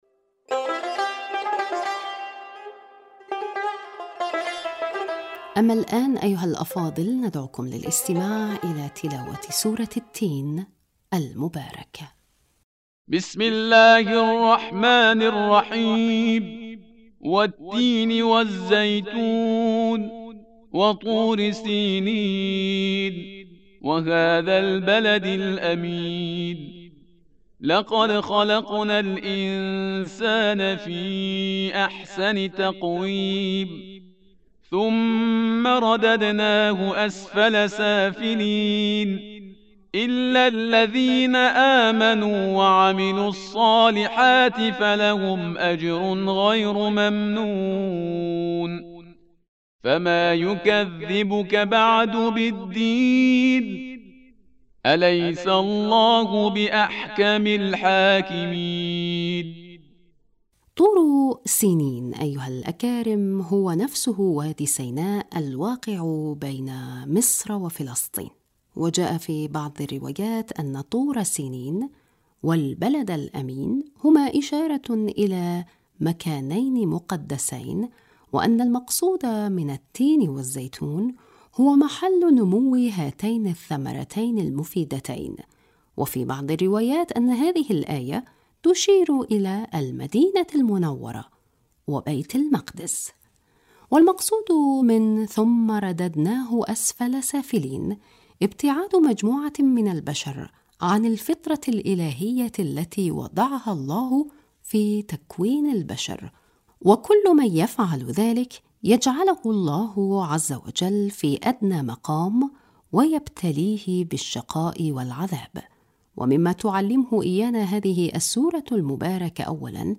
أما الآن، أيها الأفاضل، ندعوكم للإستماع الى تلاوة سورة التين المباركة..